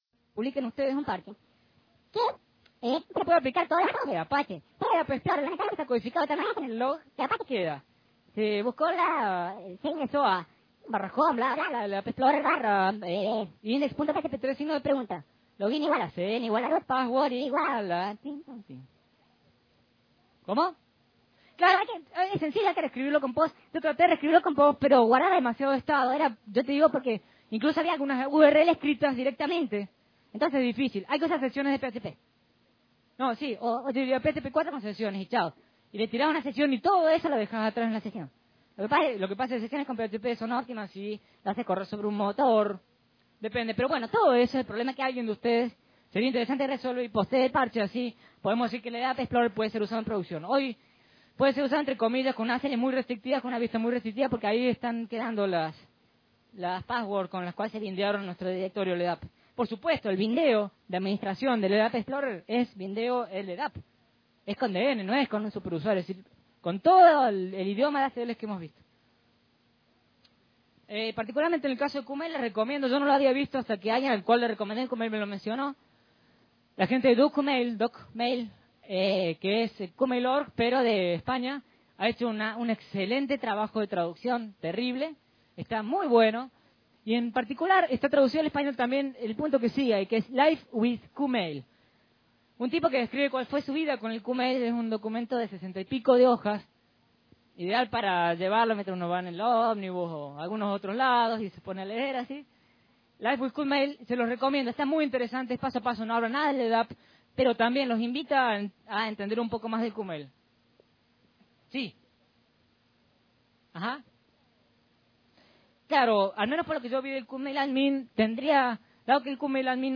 Audios: Charlas y conferencias en formato de audio
Primeras Jornadas Nacionales de Software Libre - Rosario 2000 1